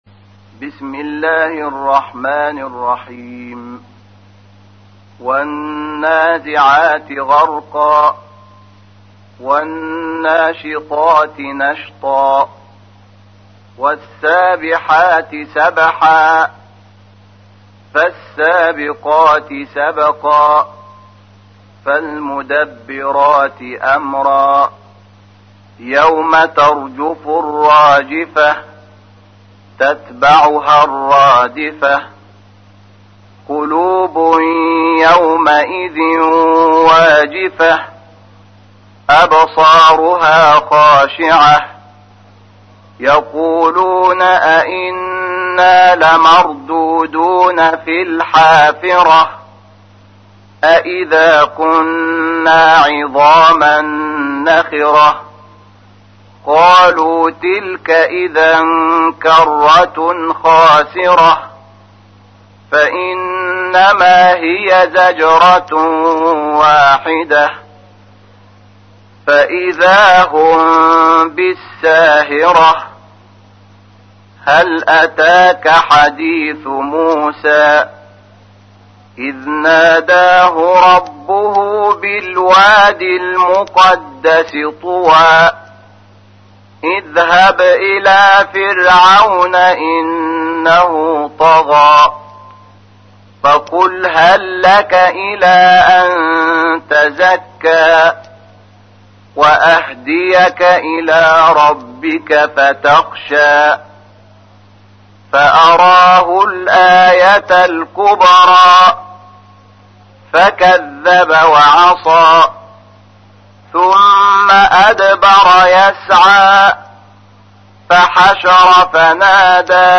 تحميل : 79. سورة النازعات / القارئ شحات محمد انور / القرآن الكريم / موقع يا حسين